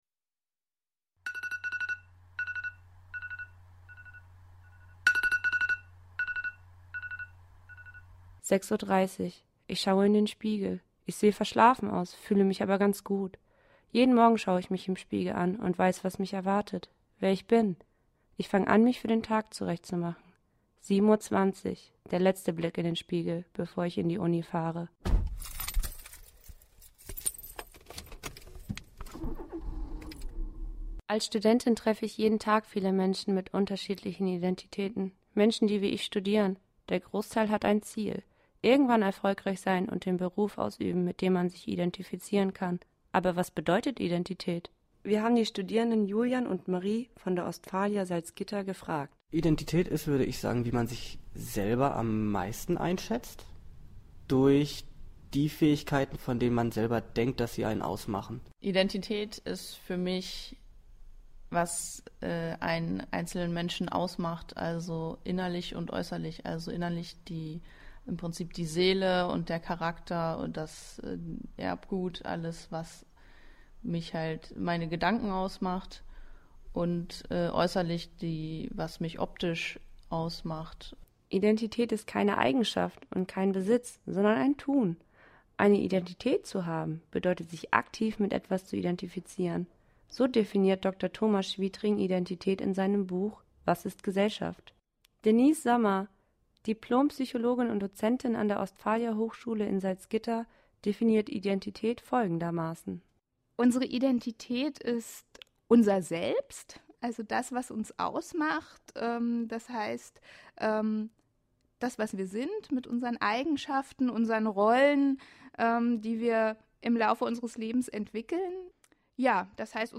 Aussehen, Persönlichkeit, Sexualität – welche Bedeutung hat Identität? Campus38 spricht mit Studierenden, Schauspielern und einer transidenten Person, was sie mit dem Begriff verbinden.